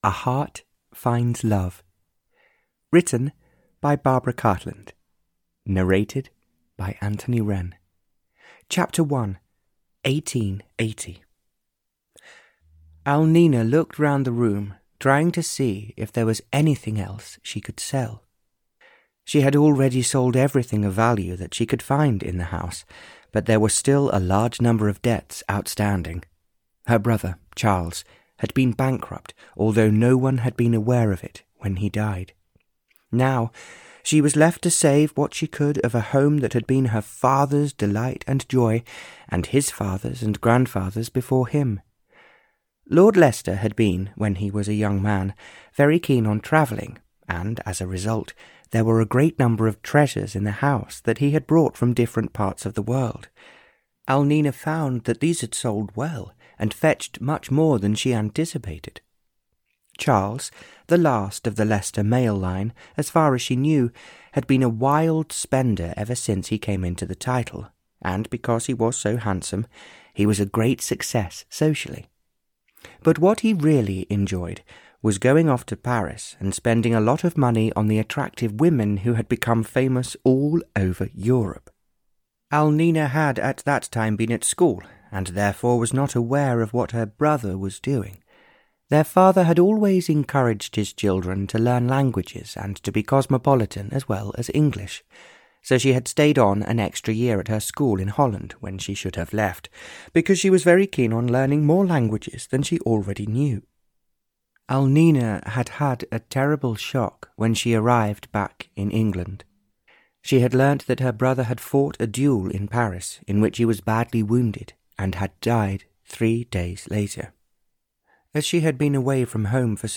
Audio knihaA Heart Finds Love (Barbara Cartland's Pink Collection 104) (EN)
Ukázka z knihy